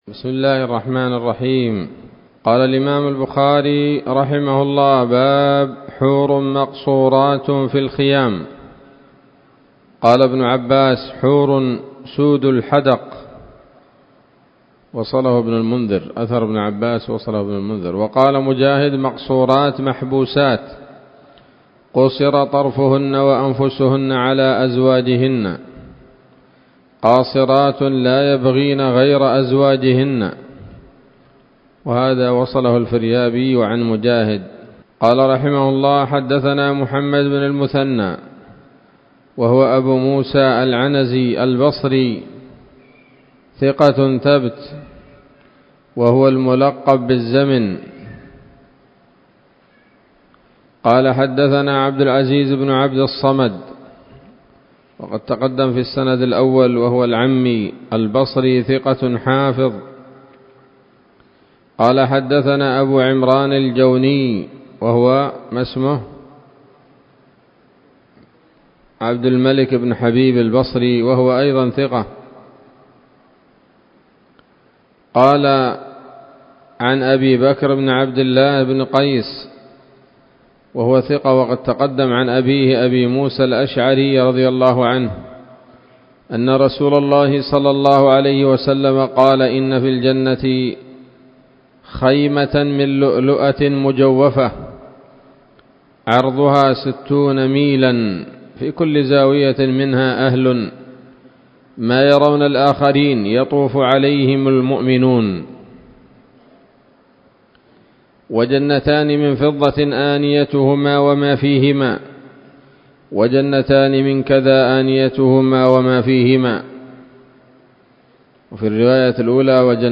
الدرس الخمسون بعد المائتين من كتاب التفسير من صحيح الإمام البخاري